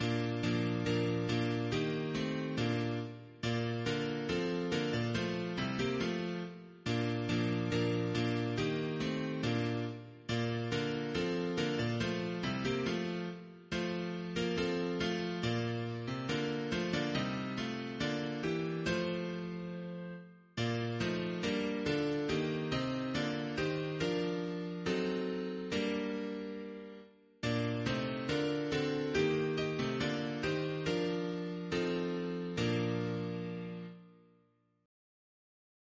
Title: Wenn in stiller Stunde Composer: Karl August Groos Lyricist: Adolf Pompecreate page Number of voices: 3vv Voicing: SATB Genre: Sacred, Chorale
Language: German Instruments: A cappella